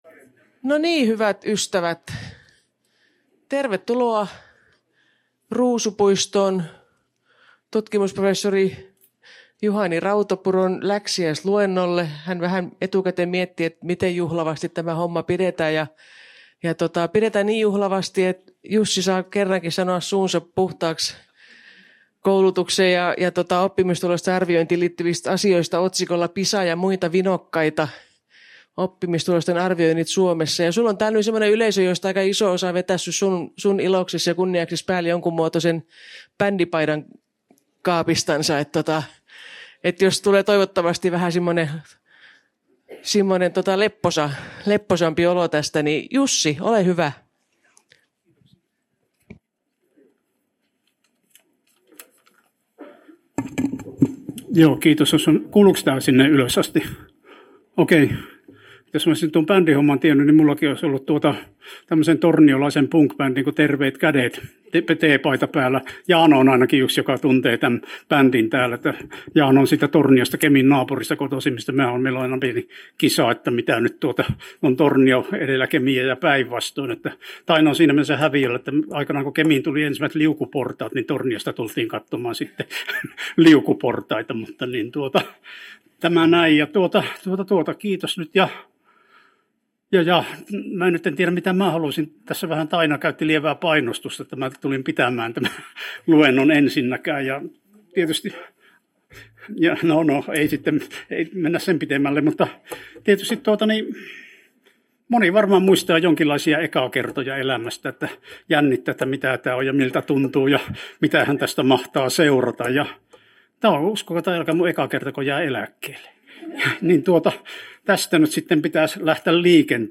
läksiäisluento